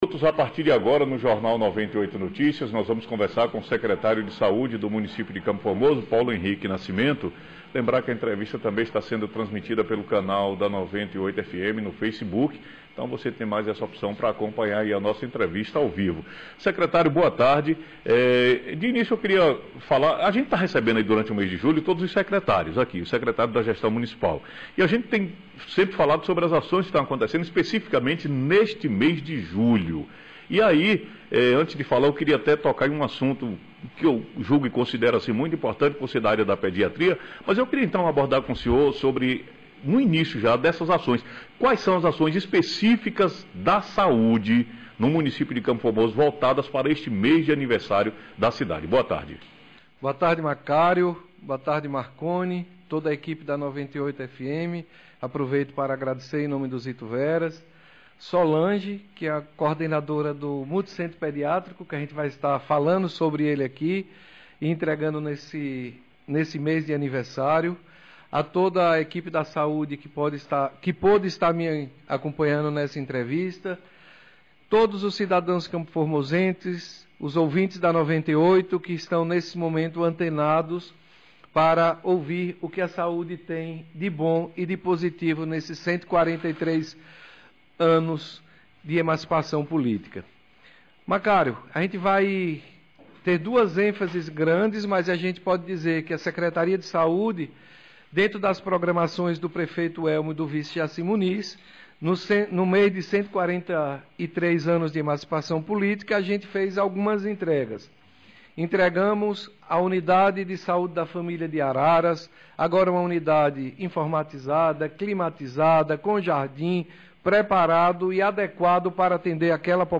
Secretário de saúde do município de CFormoso, Paulo Henrique Nascimento fala sobre a programação da secretaria no aniversário da cidade e responde ao ouvinte On Line
24-07-23-Entrevista-Secretario-Paulo-Henrique-Nascimento.mp3